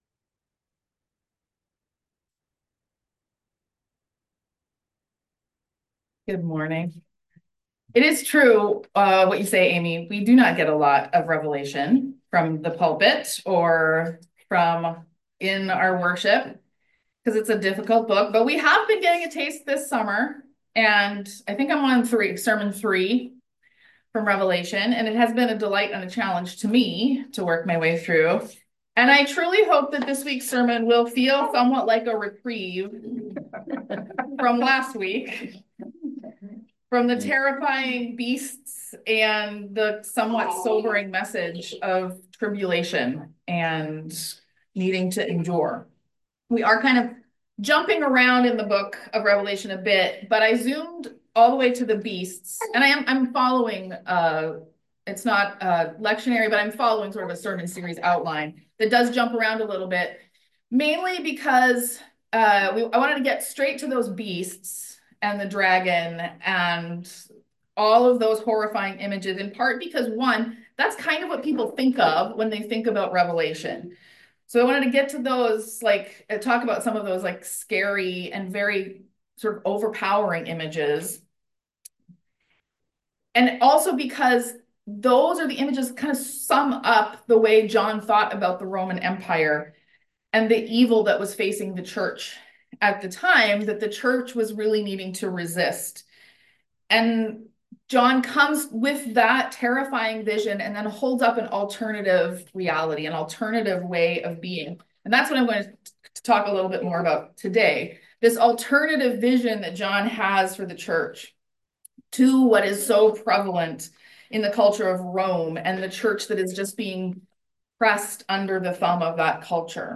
I truly hope that this week’s sermon will feel like somewhat of a reprieve from the terrifying beasts and somewhat sobering message of last week.